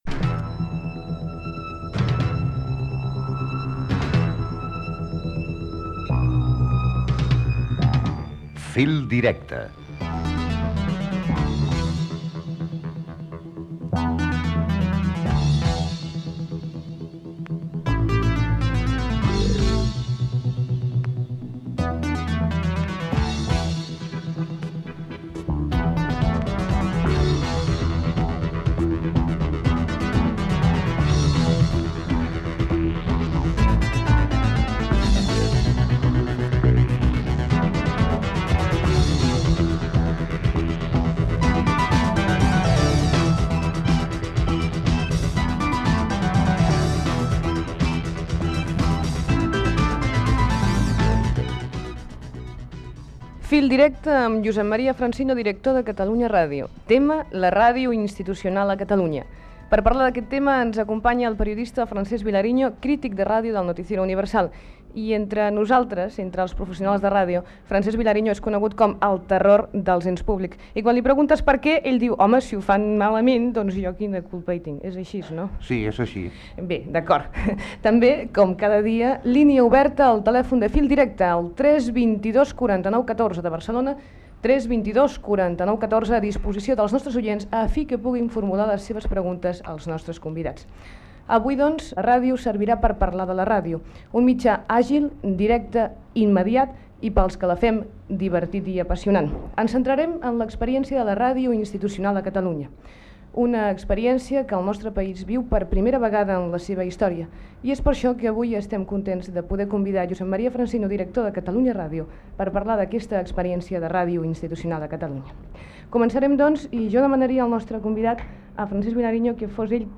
Gènere radiofònic Participació
Banda FM